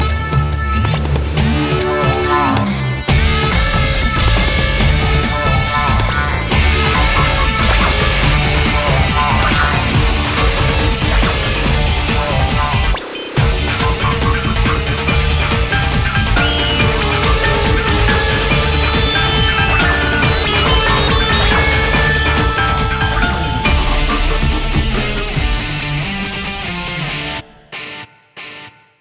• Качество: 128, Stereo
заставка